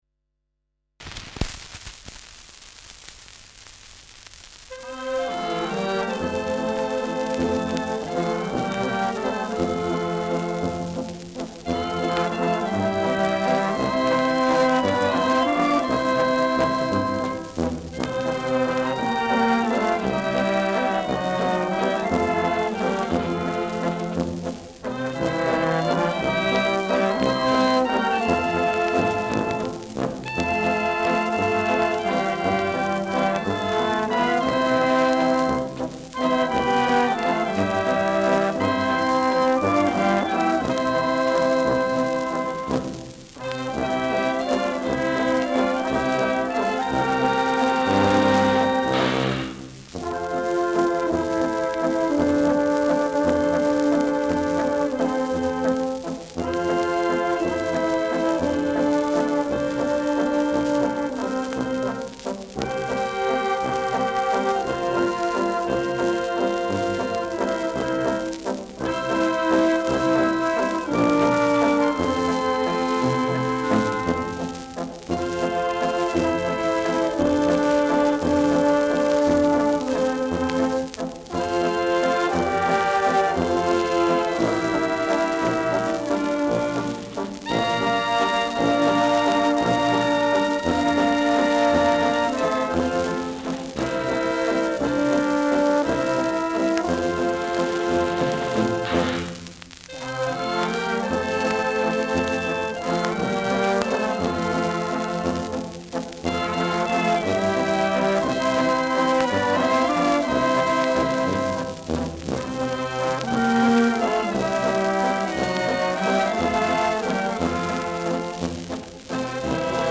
Schellackplatte
Berlin (Aufnahmeort)